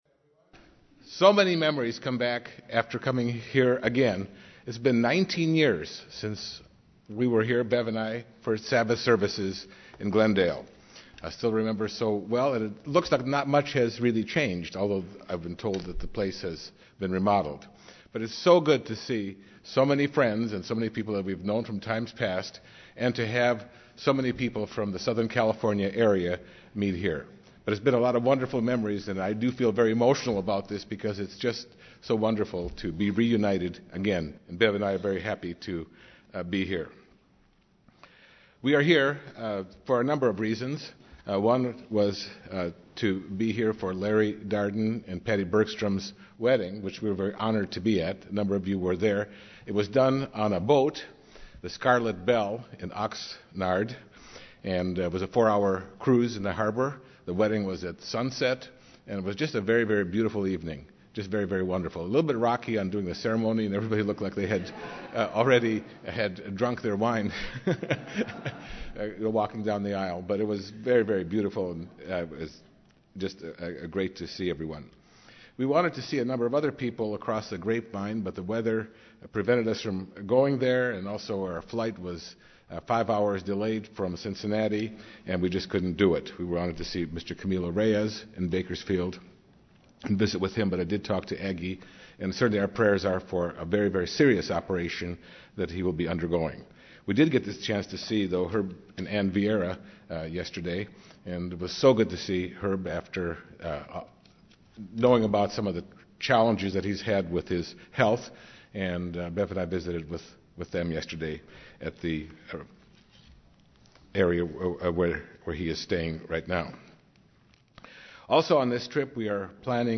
This sermon discusses the process of building of the temple of God, physical and spiritual. The Church, the Body of Christ, is now the temple of God - which includes the dwelling of God in our lives, a commitment to do righteousness, good and keep God's laws, and to be a beautiful representation of God's grace and love for mankind.